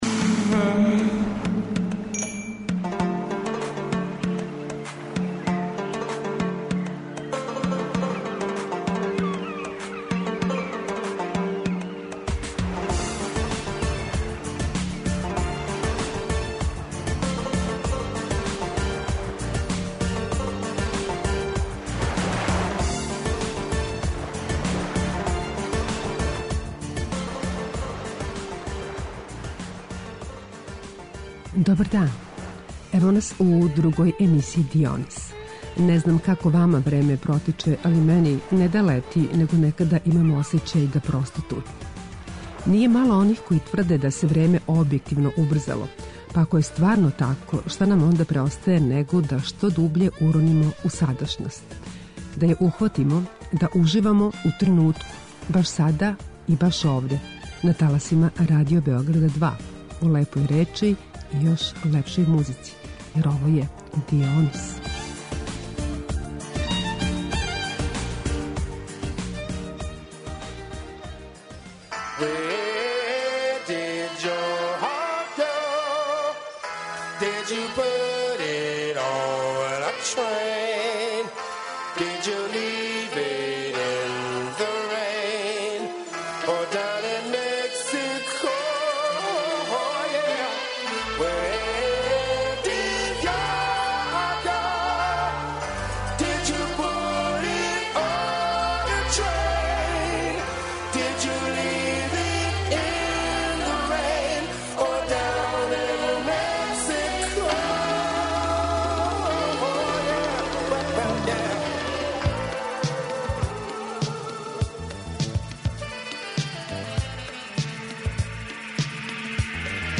И ове недеље позивамо слушаоце да уживају у лепој речи и још лепшој музици.